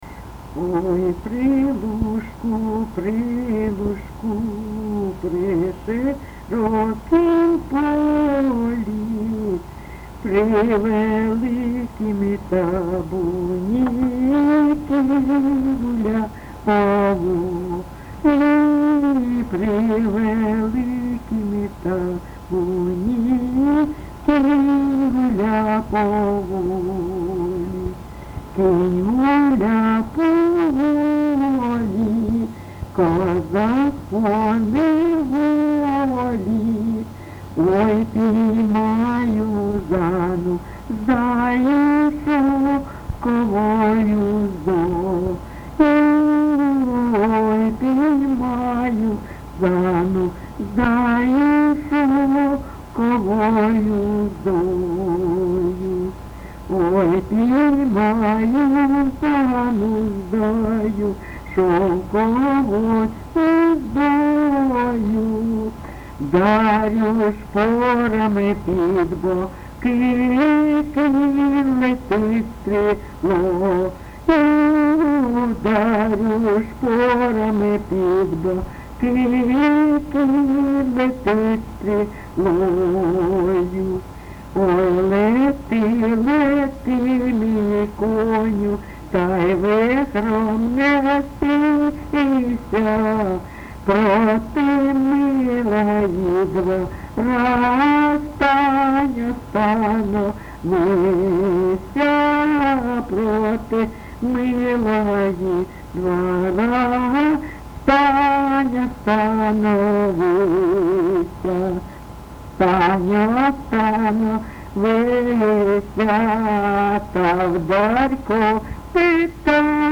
ЖанрПісні з особистого та родинного життя, Козацькі
Місце записус. Привілля, Словʼянський (Краматорський) район, Донецька обл., Україна, Слобожанщина